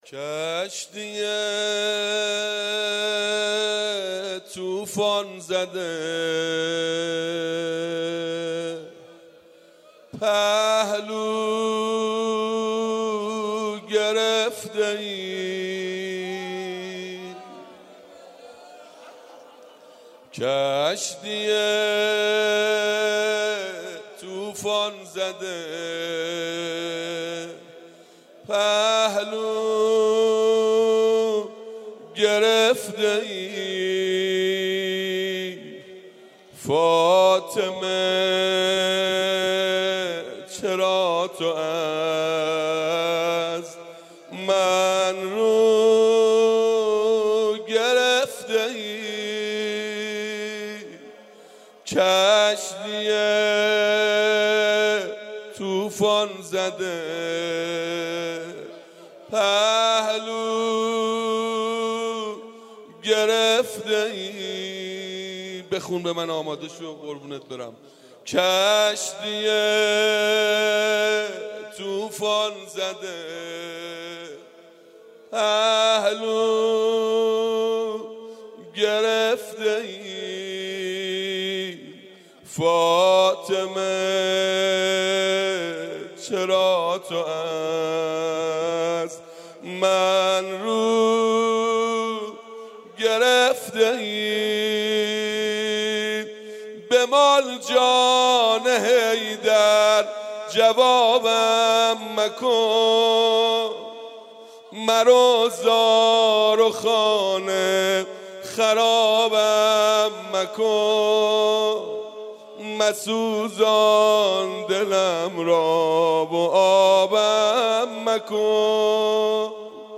روضه مداحی